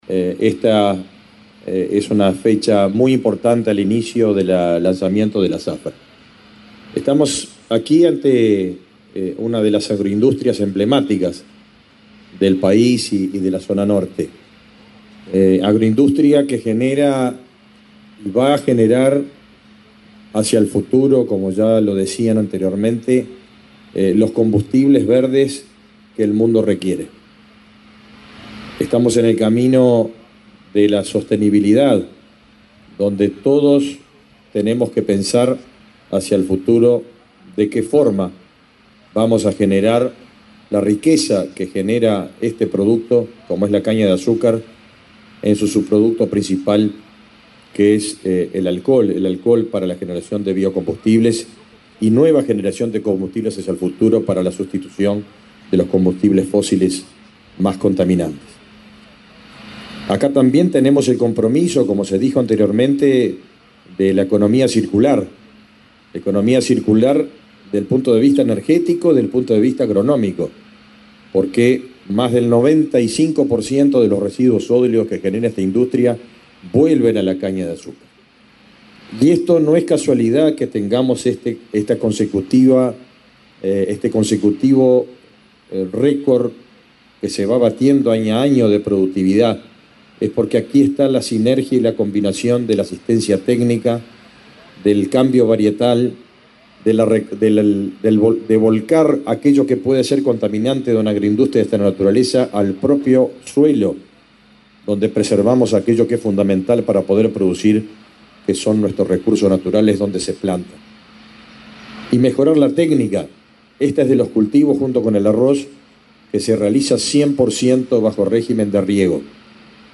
Palabra de autoridades en acto en Artigas
Palabra de autoridades en acto en Artigas 09/05/2024 Compartir Facebook X Copiar enlace WhatsApp LinkedIn El ministro de Ganadería, Fernando Mattos; su par de Industria, Elisa Facio, y el presidente de Ancap, Alejandro Stipanicic, hicieron uso de la palabra, este jueves 9 en la planta de Alur en Bella Unión, en Artigas, en el acto de inicio de la Zafra 2024 de Caña de Azúcar.